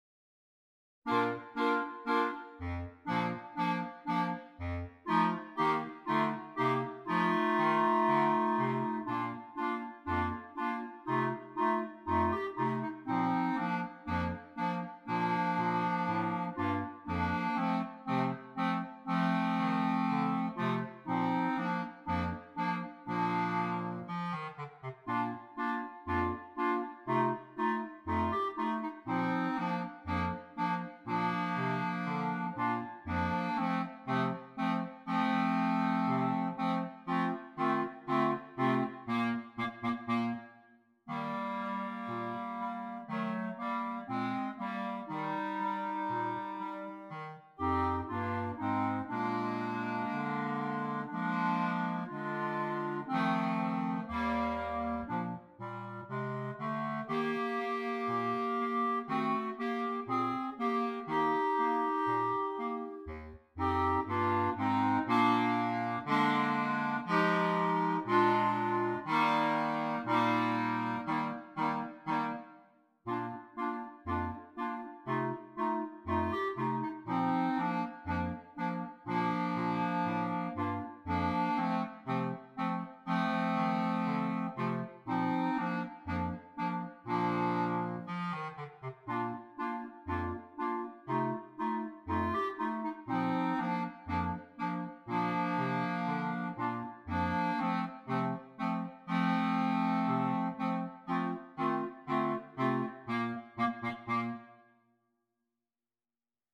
3 Clarinets, Bass Clarinet
Traditional